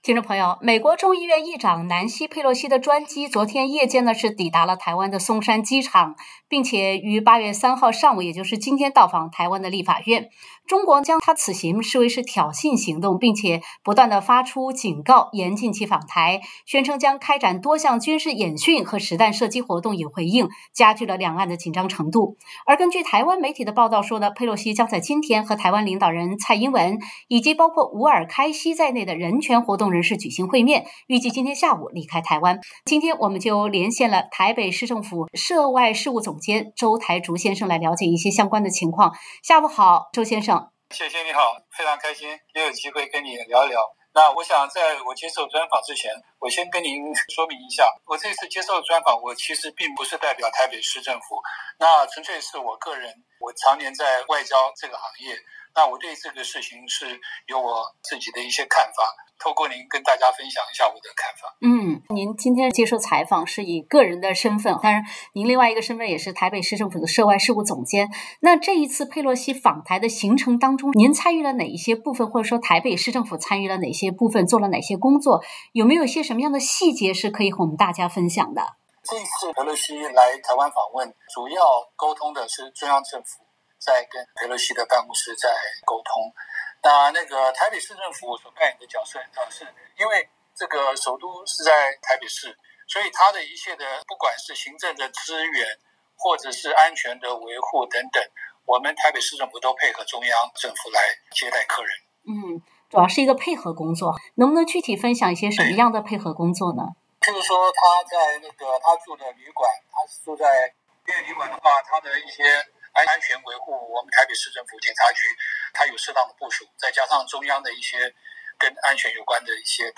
本台记者连线了台北市政府市长室涉外事务总监周台竹先生。
taipei-interview.mp3